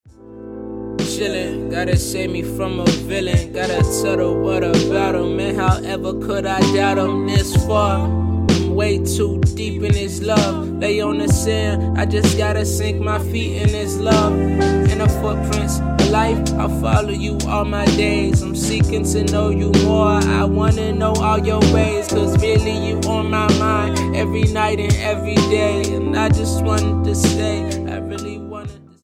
STYLE: Ambient/Meditational
rap